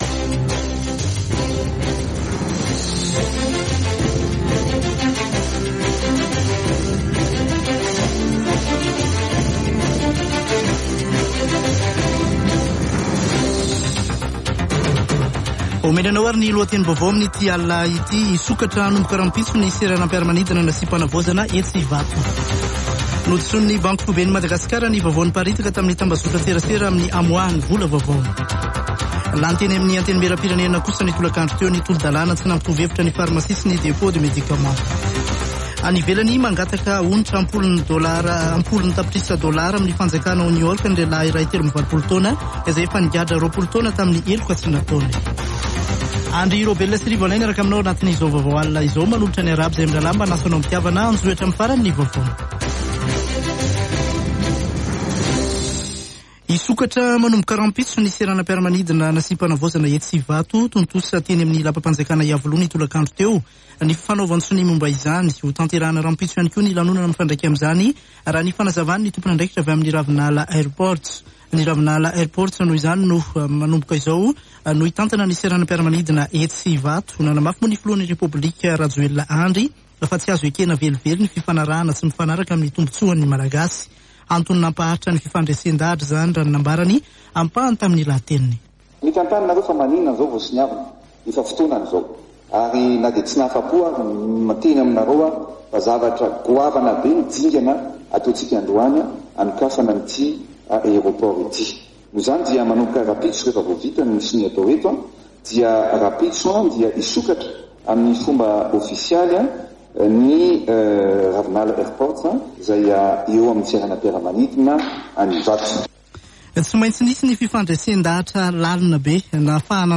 Radio Don Bosco - [Vaovao hariva] Alarobia 15 desambra 2021